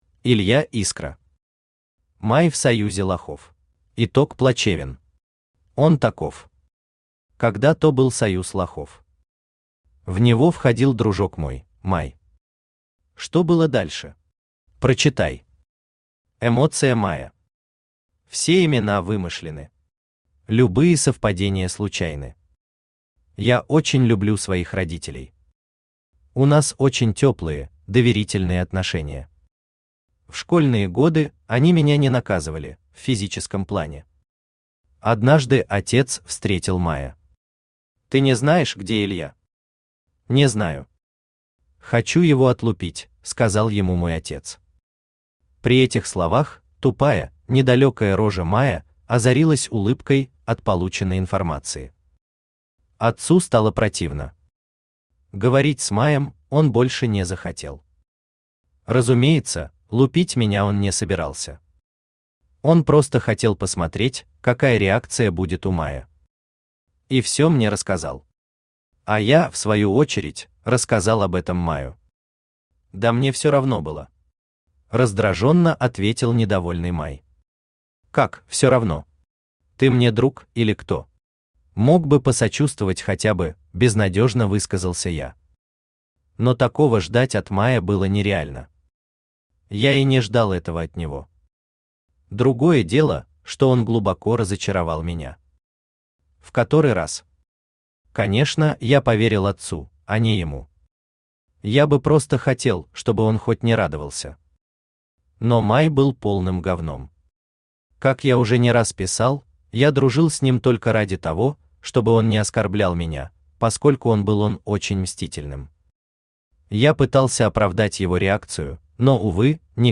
Аудиокнига Май в Союзе Лохов | Библиотека аудиокниг
Aудиокнига Май в Союзе Лохов Автор Илья Искра Читает аудиокнигу Авточтец ЛитРес.